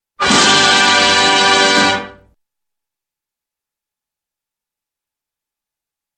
Звуки тадам
Звук торжественного та да